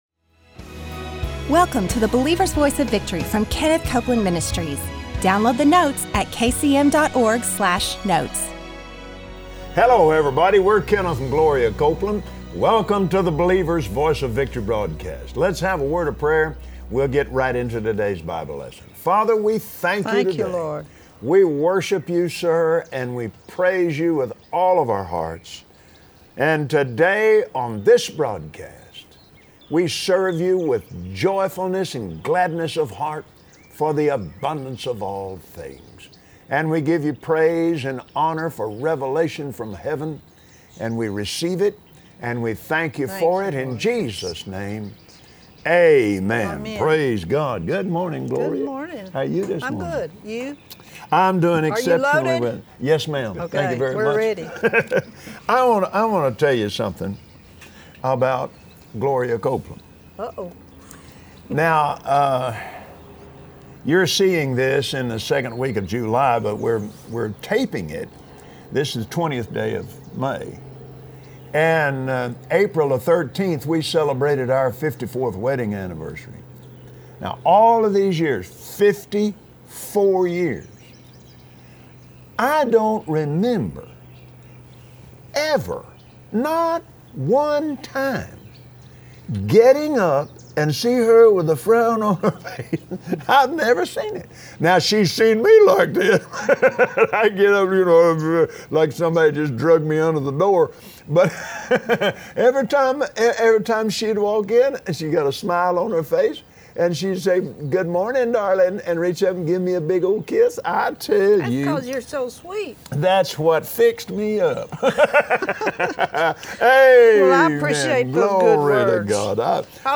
Today on the Believer’s Voice of Victory, Kenneth and Gloria Copeland, teach you how to mediate on God’s Word to renew your mind so you can have good success.